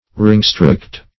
Search Result for " ringstraked" : The Collaborative International Dictionary of English v.0.48: Ringstraked \Ring"straked`\, a. Ring-streaked.